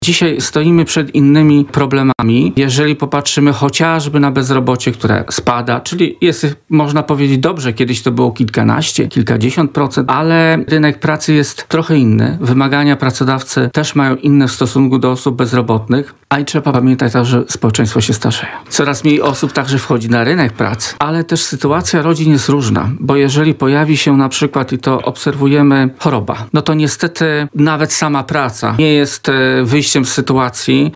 - Obecnie bieda w Polsce jest inna niż ta w latach 90. XX wieku - powiedział w porannej rozmowie Radia